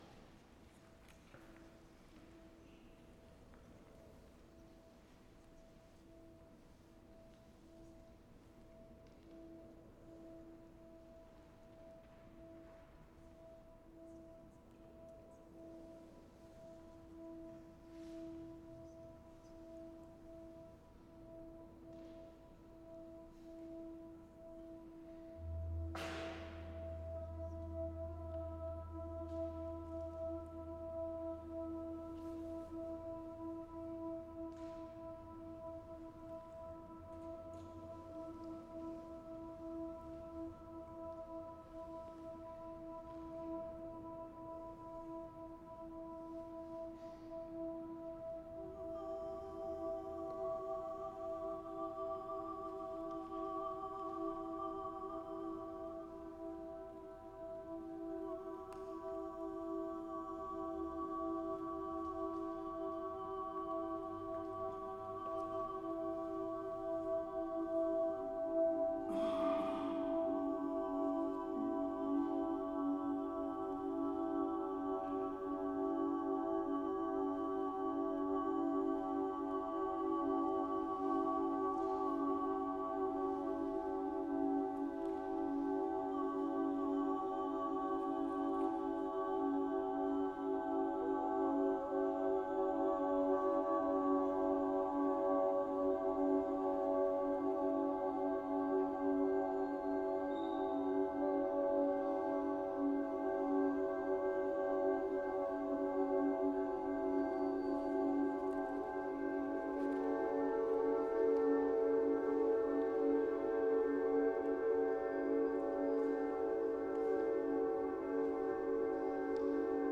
Choir and computer disc Duration: 10 min.
Witnessing the entry of my own children into this uncertain and violent world of today, I decided to set a Sanskrit text that expresses the Hindu ideal of ahimsa, or non-violence. I have set it in pitches derived entirely by ratios of 3 and 7, pitches which therefore depart considerably from the twelve-tone equal temperament standard of modern Western music. The choir sings together with these tones played by the computer-generated accompaniment on CD, recapitulating the transitions of luminescence, of children still too close to the divine to set their feet on earth.